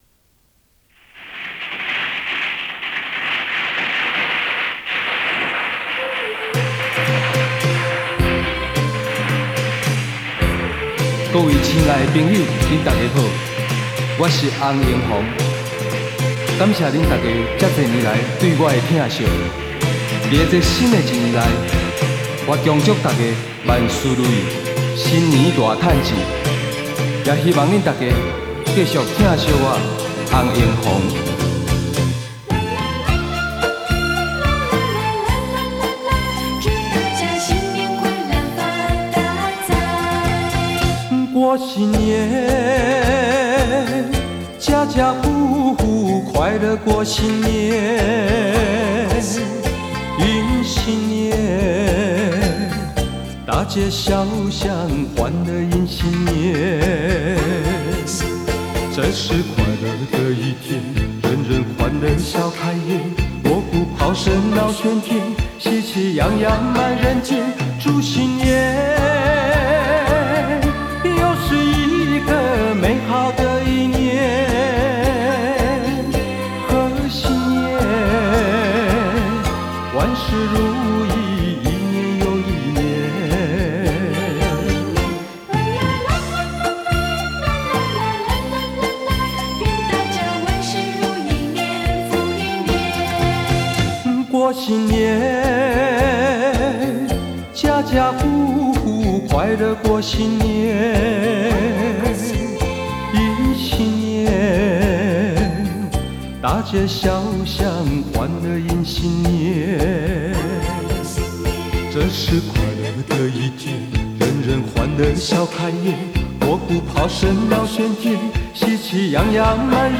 传统气氛 精彩动听